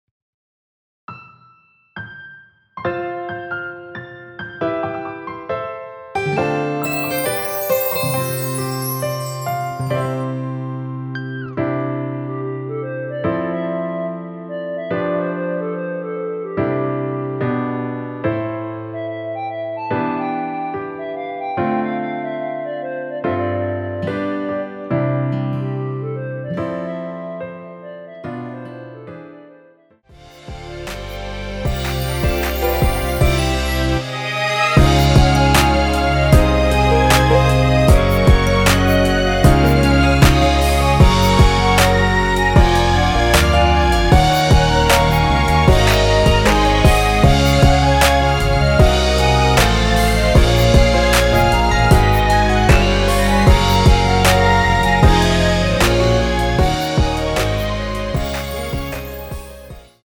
원키에서(+5)올린 멜로디 포함된 MR입니다.
노래방에서 노래를 부르실때 노래 부분에 가이드 멜로디가 따라 나와서
앞부분30초, 뒷부분30초씩 편집해서 올려 드리고 있습니다.